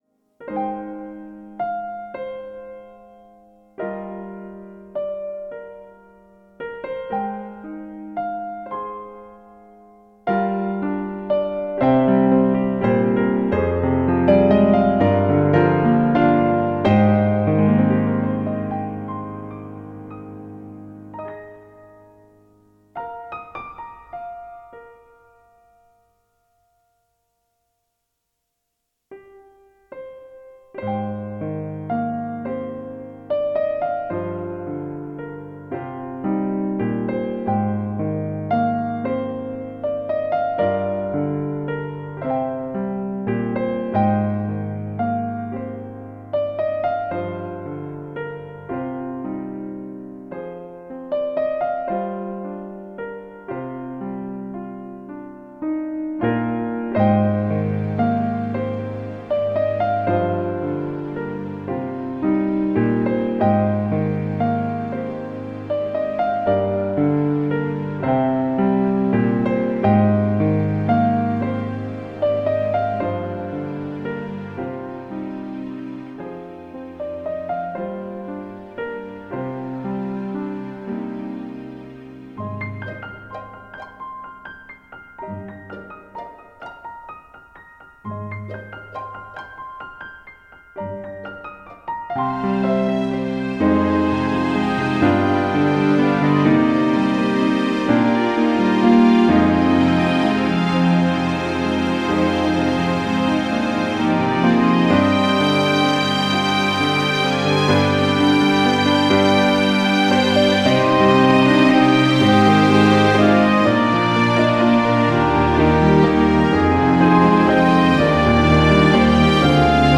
【切ない】【ピアノ】【シリアス】